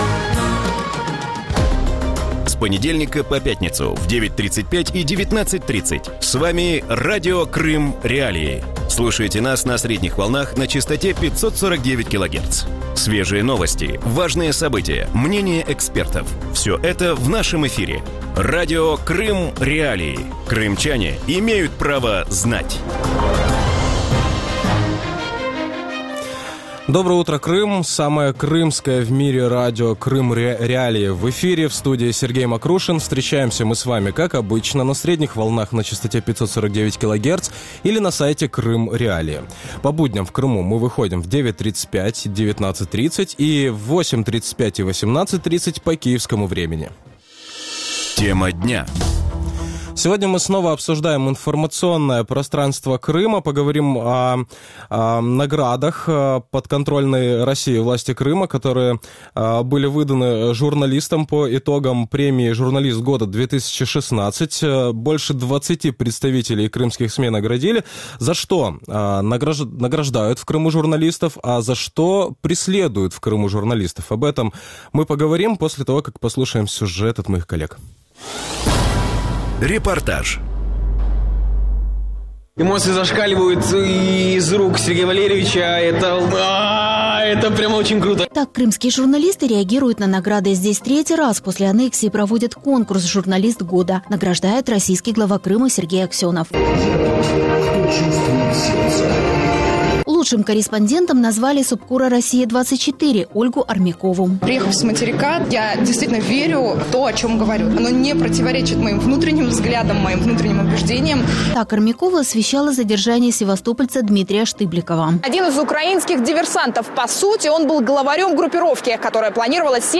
Вранці в ефірі Радіо Крим.Реалії говорять про нагороди підконтрольної Росії влади Криму журналістам які сьогодні працюють в анексованому Криму.